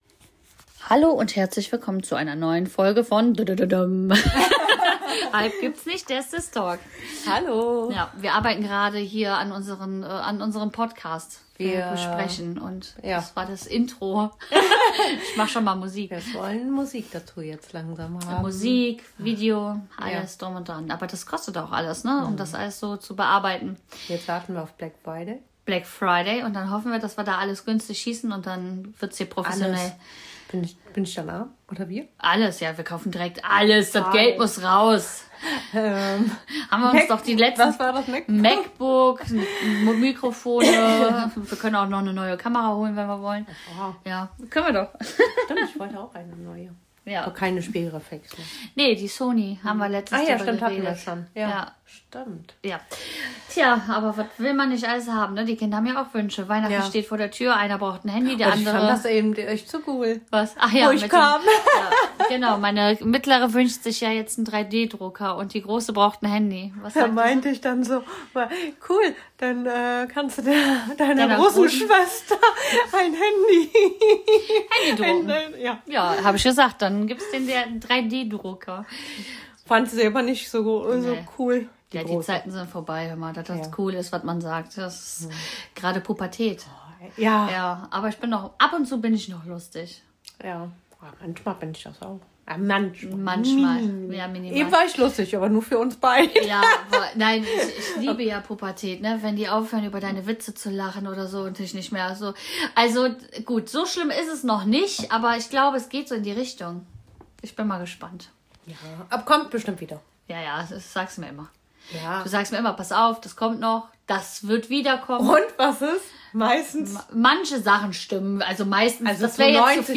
In dieser Podcastfolge reden zwei Schwestern über ihren Mädels-Tag und teilen lustige Geschichten aus ihrem Alltag. Dabei diskutieren sie, ob Horrorfilme Spaß machen oder einfach nur gruselig sind.